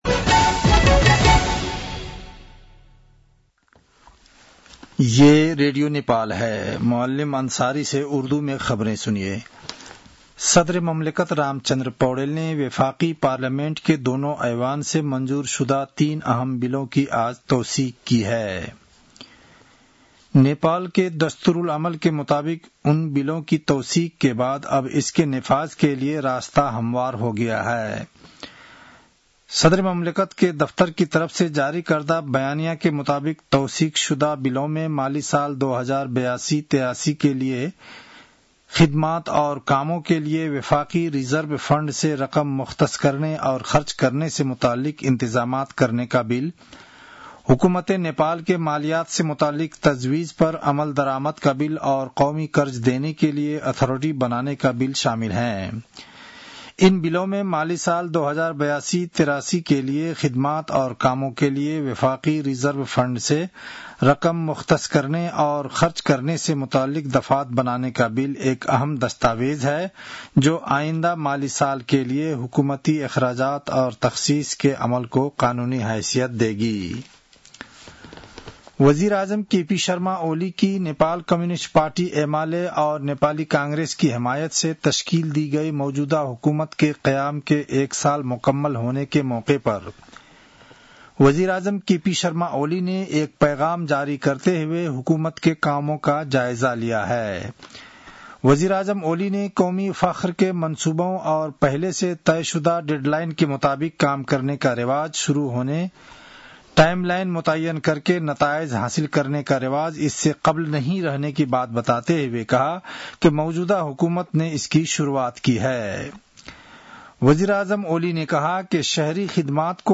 उर्दु भाषामा समाचार : ३० असार , २०८२
Urdu-news-3-30.mp3